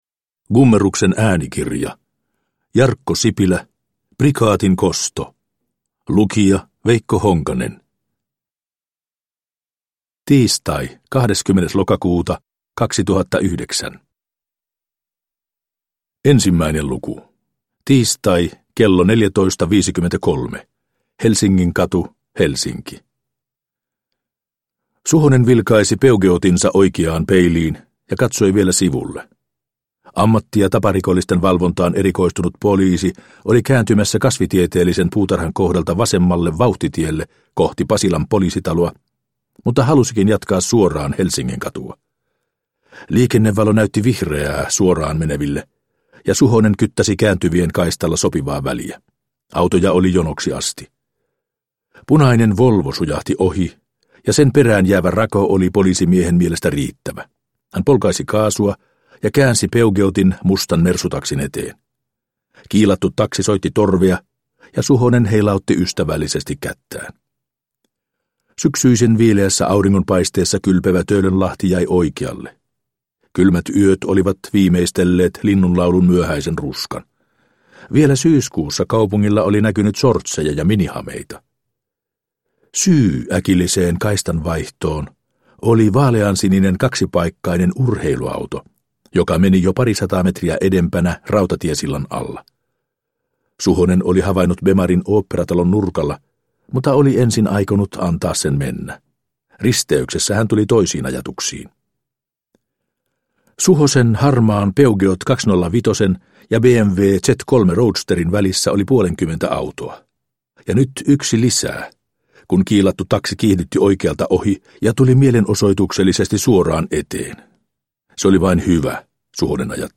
Prikaatin kosto – Ljudbok – Laddas ner